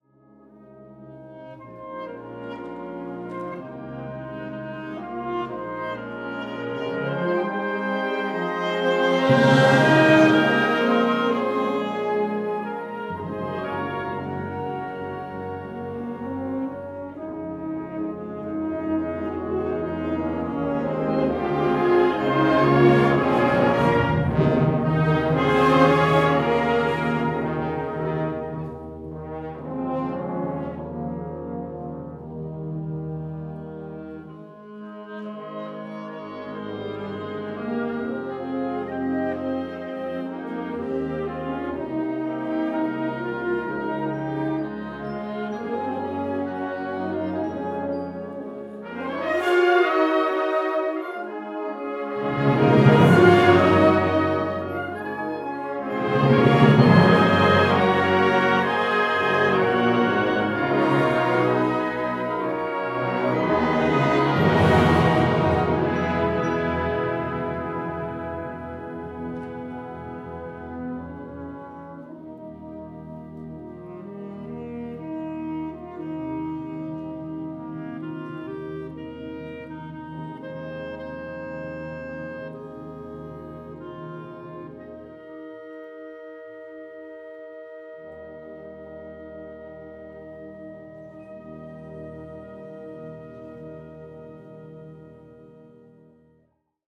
Frühlingskonzert 2025